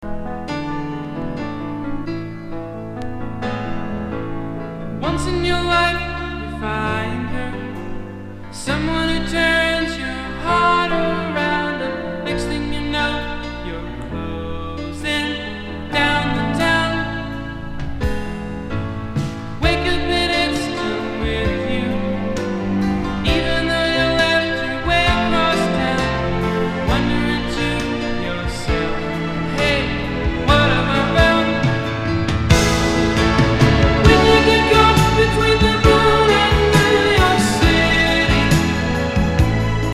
ORIGINAL SOUNDTRACK